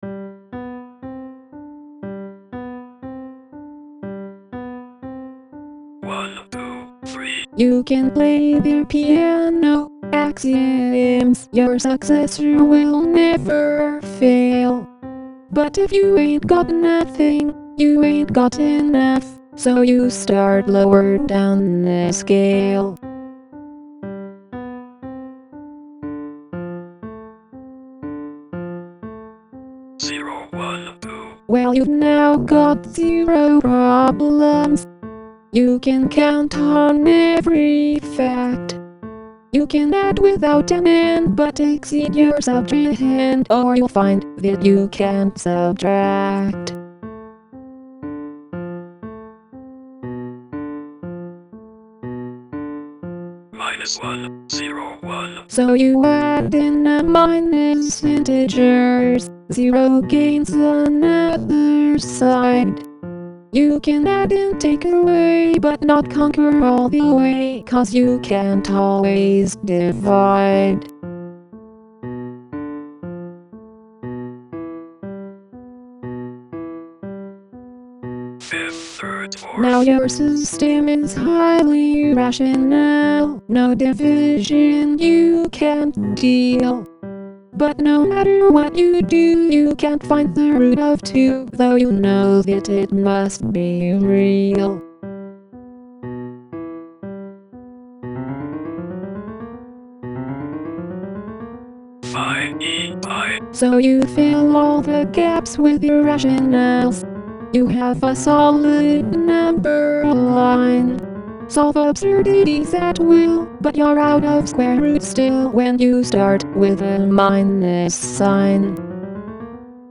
This post’s mathematics article is entitled ‘Some Fundamental Mathematical Definitions’ and the poetic form is air, which is a kind of song.
This song covers the first few sections of the article, about the development of the various number sets (Natural numbers [which I learnt as not including zero], whole numbers [including zero], integers, rational numbers, real numbers, and complex numbers) and finally a little abstract algebra. I’ve made a recording of it [direct mp3 link] using my robot choir and some instruments in GarageBand. I didn’t follow all the suggestions relating to airs, but one hallmark of an air is ‘illustrative musical devices highlighting specific words’, and I went overboard on that, illustrating each set using the background music. Airs are typically accompanied by a lute or other plucked instrument, but I used a piano instead, to highlight the word ‘Peano‘ in the first line.
I made several improvements to my robot choir (an app I wrote one weekend to get my Mac to sing for me) including fixing a silly bug which had thrown the timing of my previous recordings off.
I changed some parts to make it less similar, but mostly I just made it more repetitive and annoying.